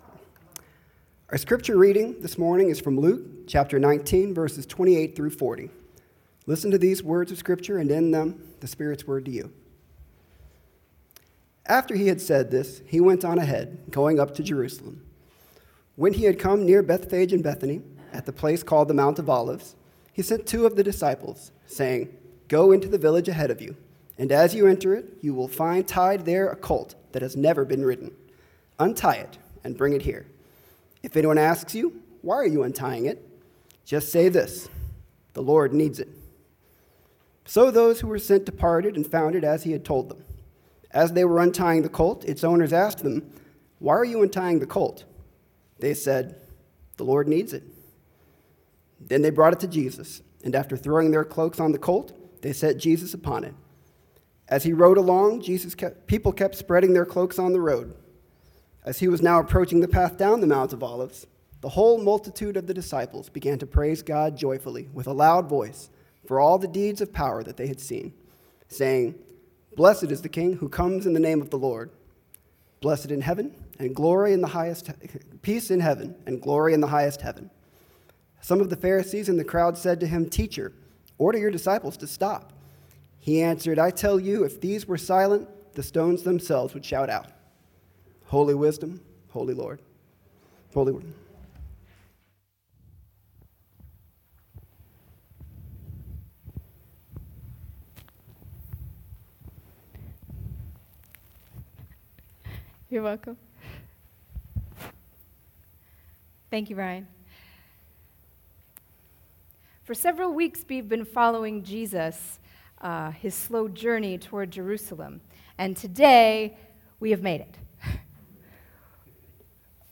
Sermon April 10, 2022 (Palm Sunday)